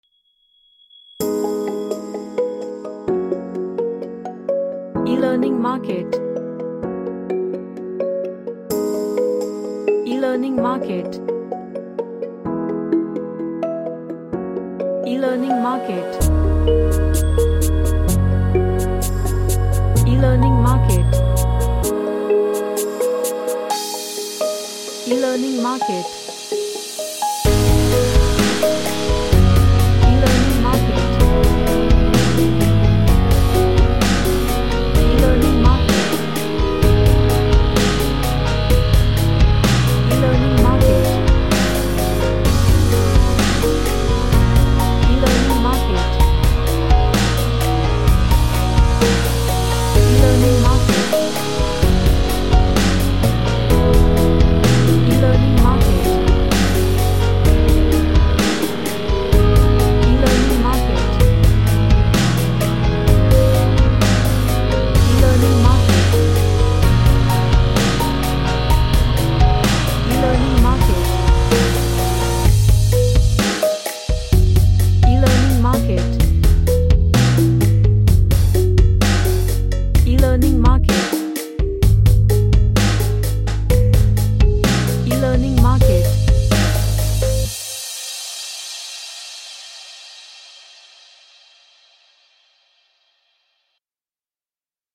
A electronic cinematic track with atmospheres
Emotional